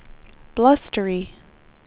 speech / tts / prompts / voices